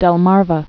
(dĕl-märvə)